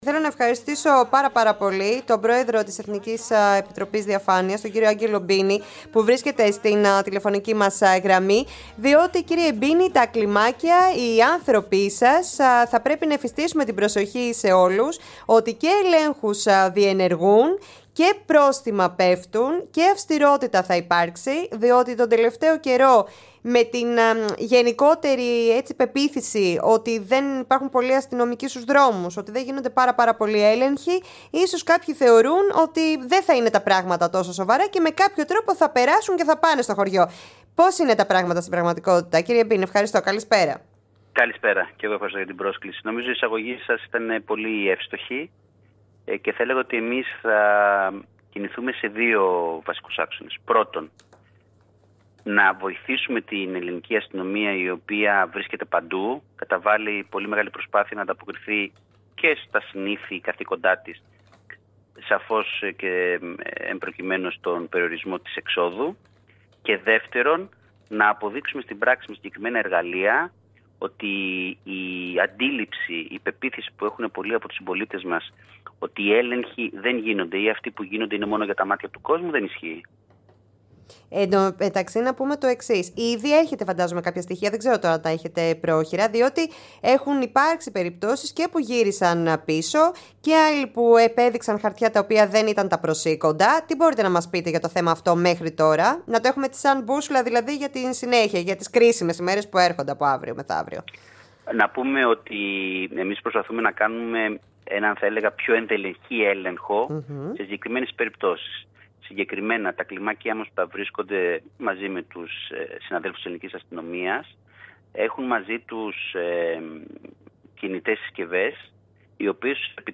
O Διοικητής της ΕΑΔ στην Ρ/Φ εκπομπή του Πρώτου Θέματος